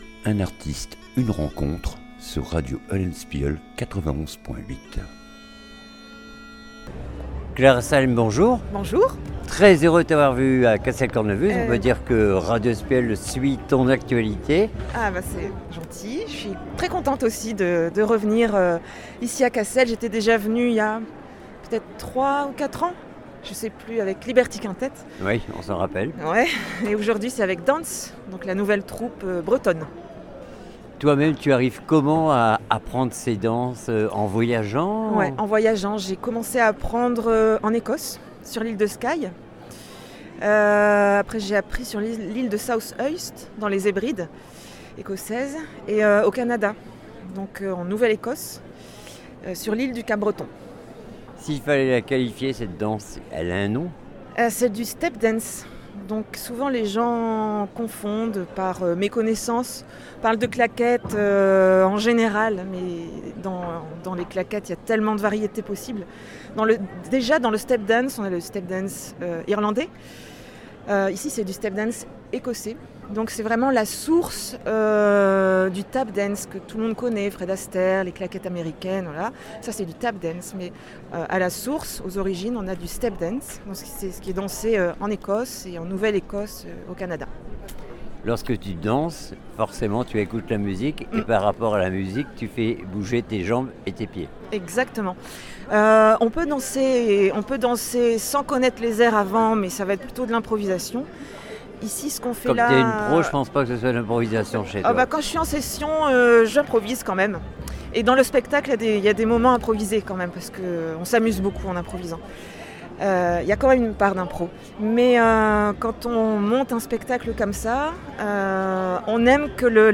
RENCONTRE LORS DU FESTIVAL CASSEL CORNEMUSES 2025